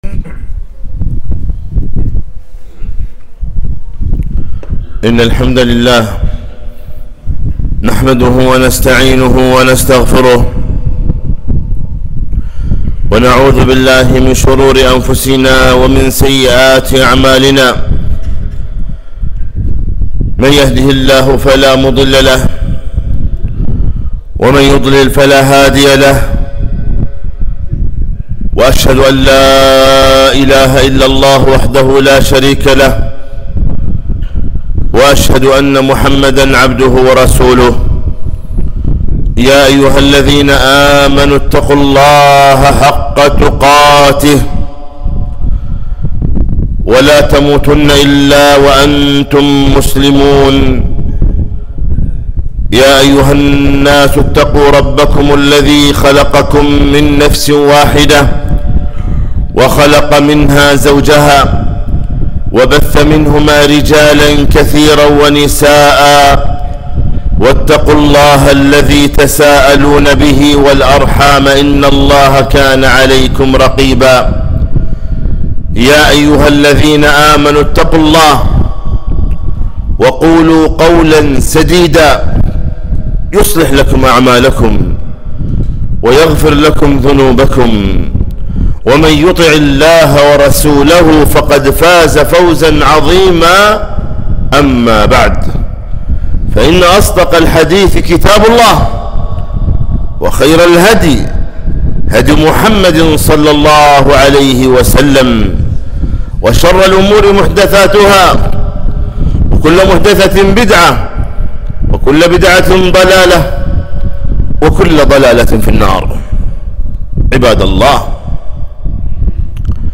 خطبة - شهر القرآن